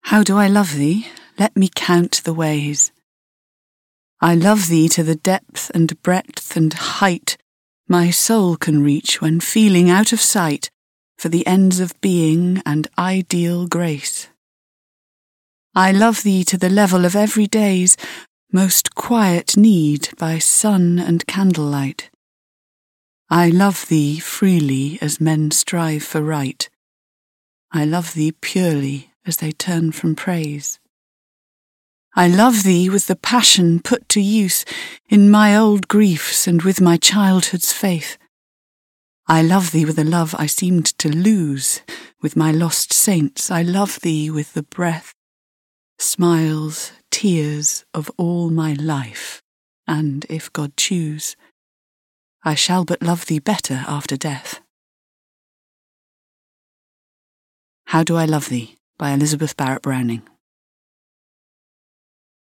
reads this Emily Dickinson sonnet